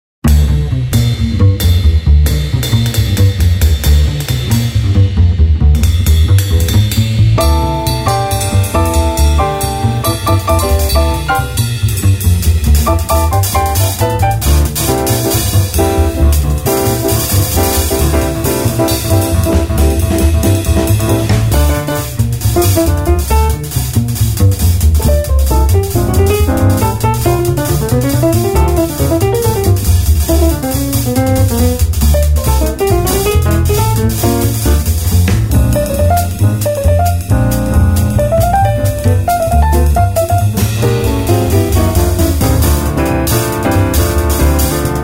piano
bass
drums
Recorded at Avatar Studio in New York on April 26 & 27, 2010